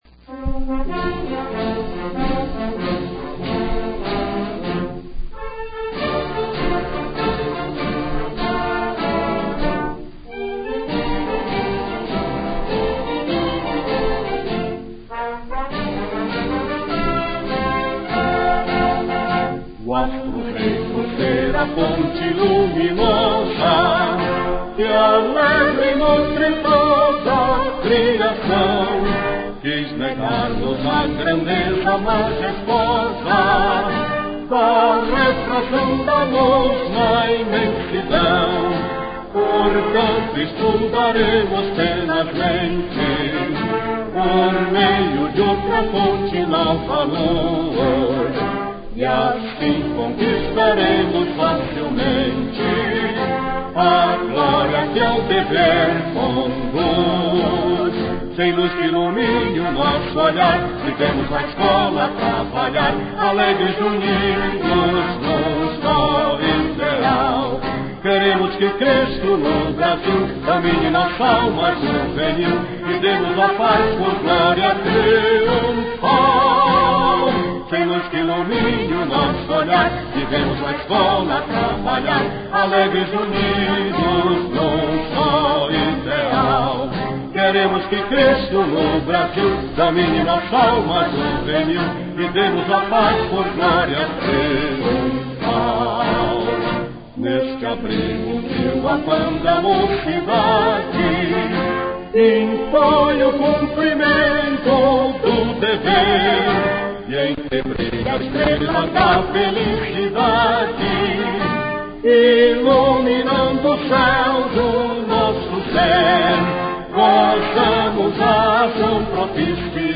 CANTADO (MP3) TAMANHO DURAÇÃO FLAUTA (MP3) TAMANHO DURAÇÃO